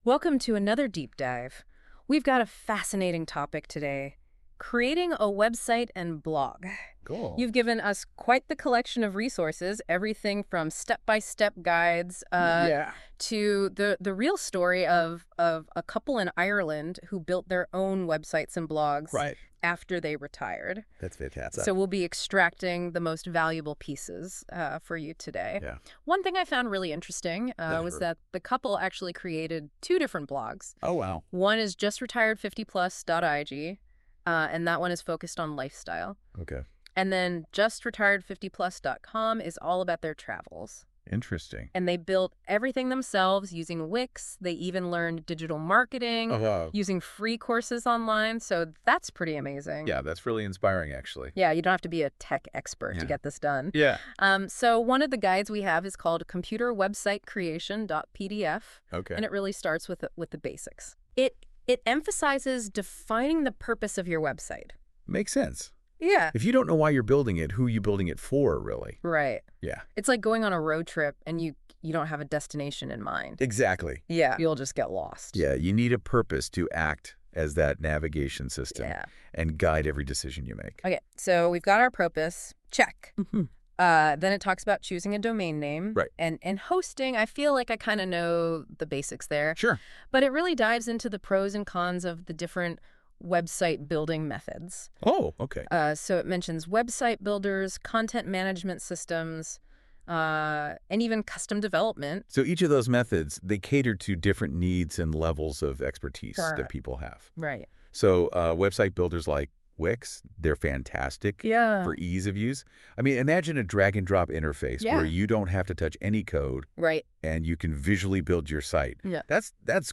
PodCast of this blog in conversation form available for easy listening ( Link from icon above ) Learn how to build your own BLOG Creating a website may seem daunting at first, but with a clear plan and the right tools, you can build a site that serves your goals and impresses your audience.